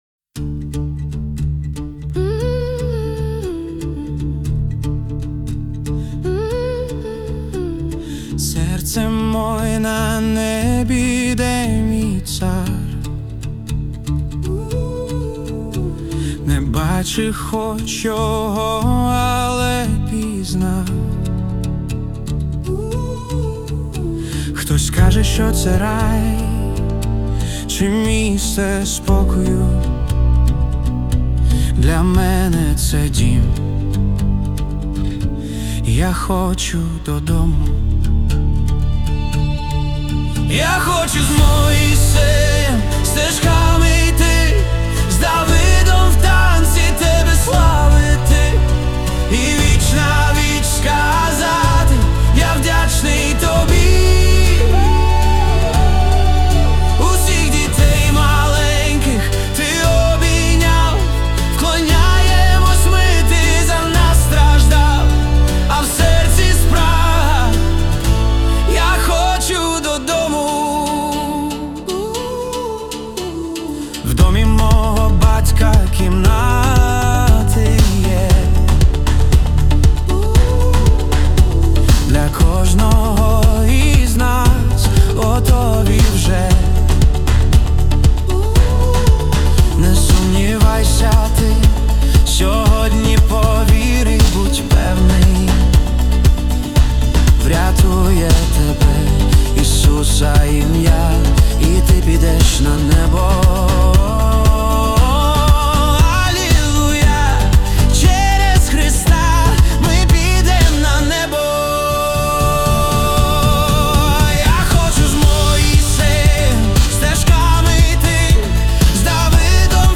песня ai
15 просмотров 74 прослушивания 2 скачивания BPM: 117